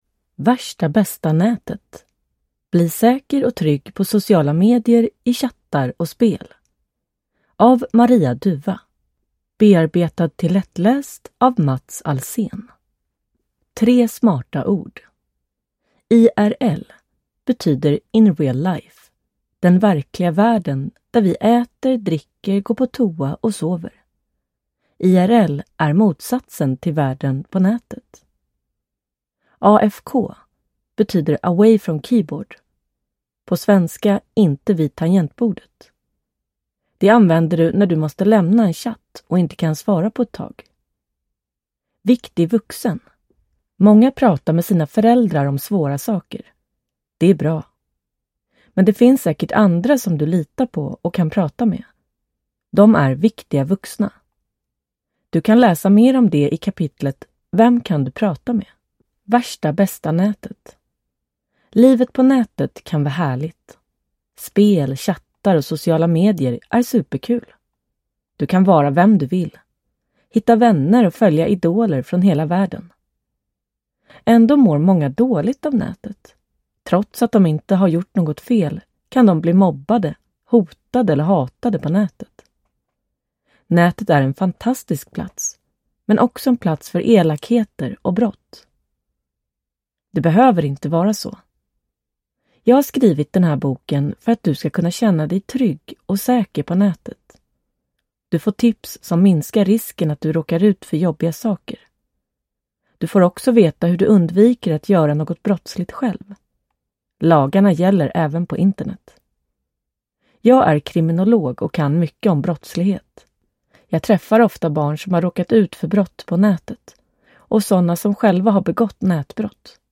Värsta bästa nätet (lättläst) : Bli säker och trygg på sociala medier, i chattar och spel – Ljudbok – Laddas ner